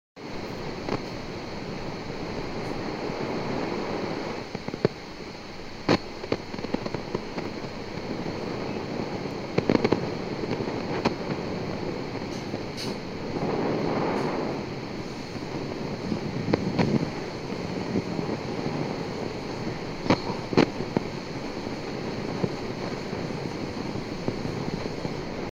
Noise from microphone
I am using AKG P120 condenser microphone that I bought yesterday. I am getting a very weird noise from the microphone.
I have attached the noise along with this thread.